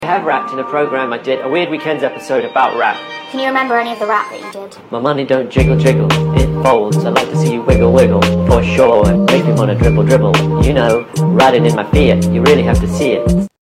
Speed up songs part 5